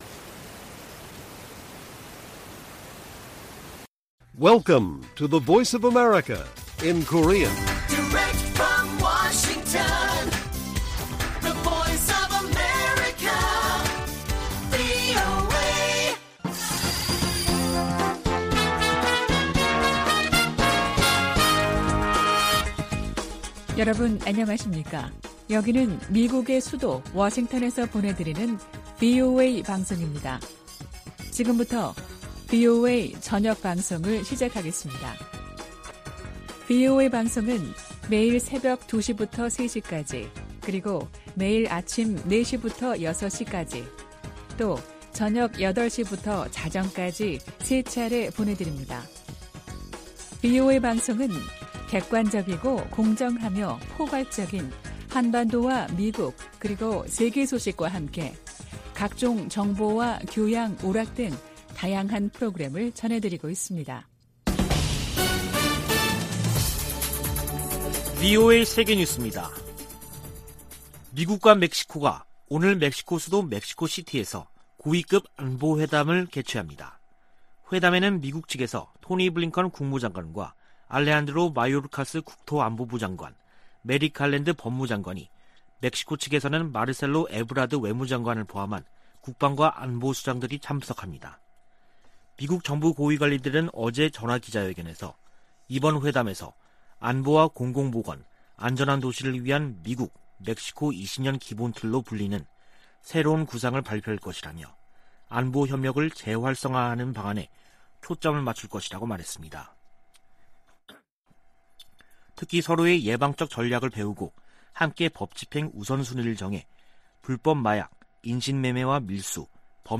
VOA 한국어 간판 뉴스 프로그램 '뉴스 투데이', 2021년 10월 8일 1부 방송입니다. 코로나 방역 지원 물품이 북한에 도착해, 남포항에서 격리 중이라고 세계보건기구(WHO)가 밝혔습니다. 대북 인도적 지원은 정치 상황과 별개 사안이라고 미 국무부가 강조했습니다. 북한에서 장기적인 코로나 대응 규제 조치로 인권 상황이 더 나빠졌다고 유엔 북한인권 특별보고관이 총회에 제출한 보고서에 명시했습니다.